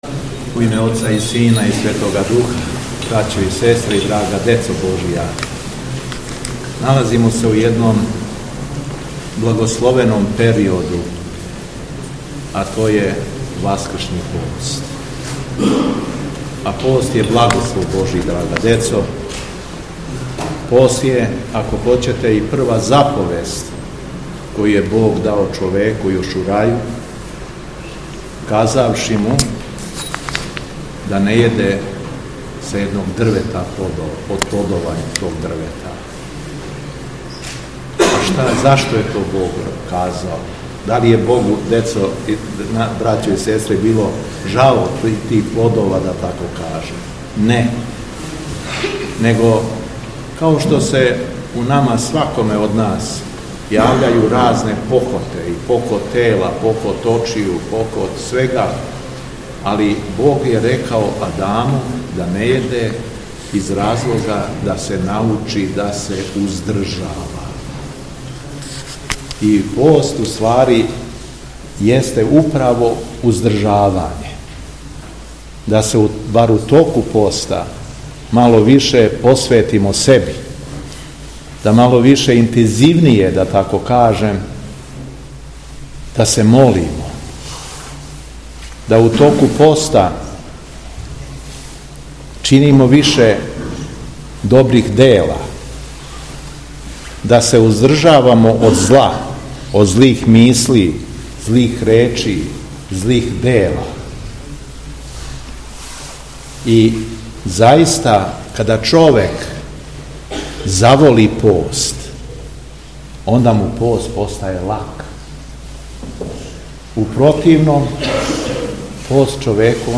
У среду, треће недеље Часног поста, 19. марта 2025. године, Његово Високопреосвештенство Митрополит шумадијски Г. Јован служио је Литургију Пређеосвећених дарова у храму Свете Тријице у Рогачи.
Беседа Његовог Високопреосвештенства Митрополита шумадијског г. Јована
Велики број сабраног верног народа, као и деце овога краја, дочекао је свога Архијереја и узео активног учешћа у богослужбеном сабрању.